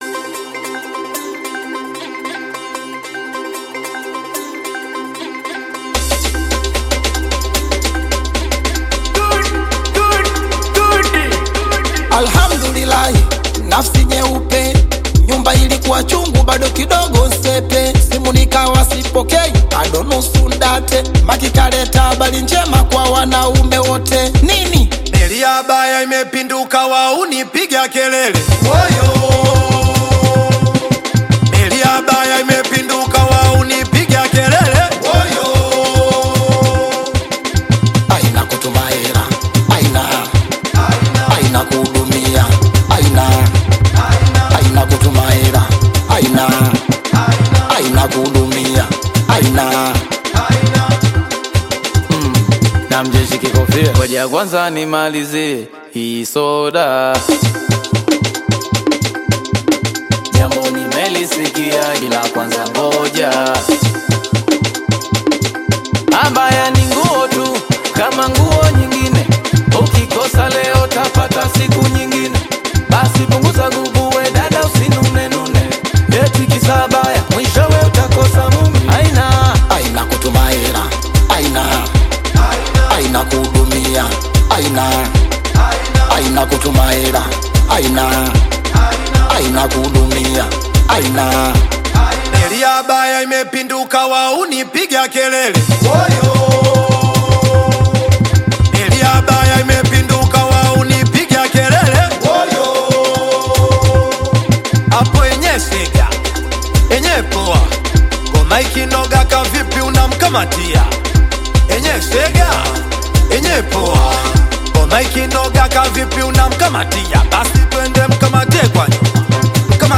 Singeli music track